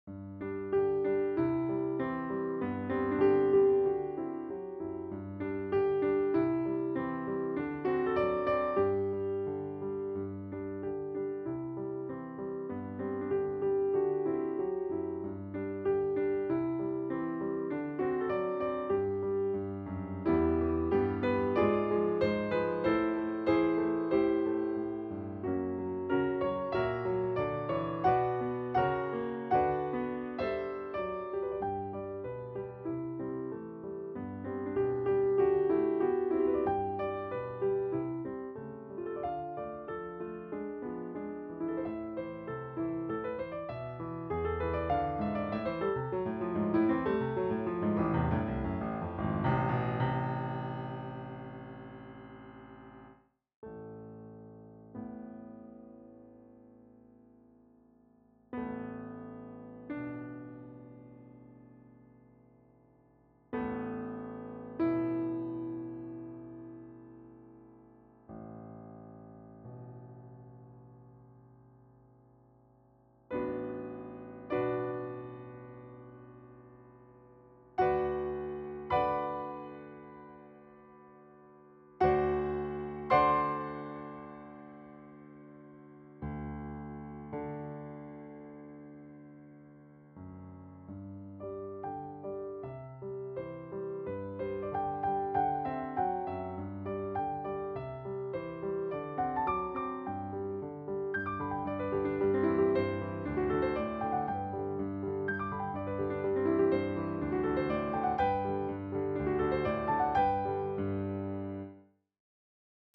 No. 36 "Kindertanz" (Piano